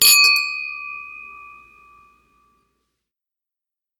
Crystal Glasses Ding Cheers 1
brittle celebration cheers cling ding drink effect glass sound effect free sound royalty free Sound Effects